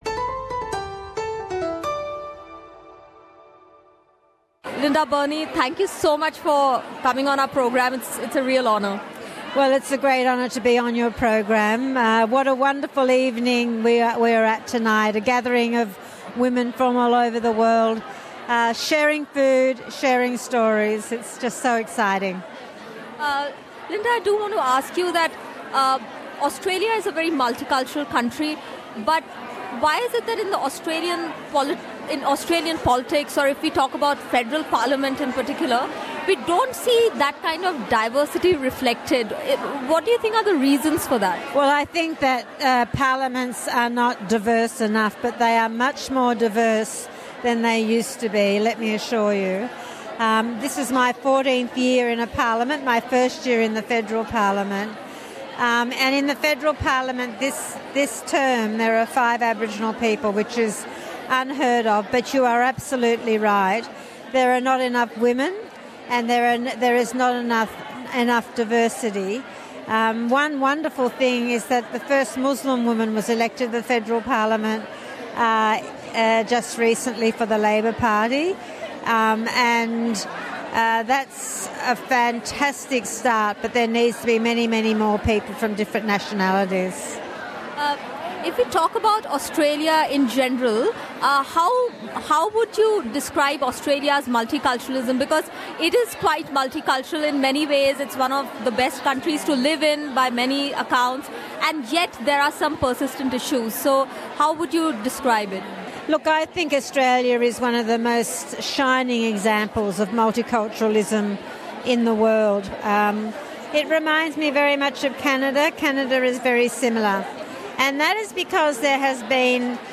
Tune in for this very special interview with Ms Burney to know her views on Australian Multiculturalism, Indigenous Issues and many things Indian!...